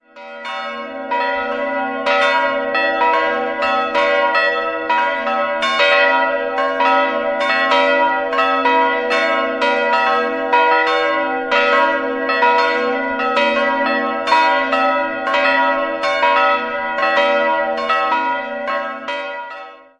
Erbaut wurde die Kirche in den Jahren 1957/58 vom Münchner Architekten Olaf Andreas Gulbransson. 3-stimmiges Gloria-Geläut: h'-cis''-e'' Das Geläut wurde 1958 von Karl Czudnochowsky in Erding gegossen.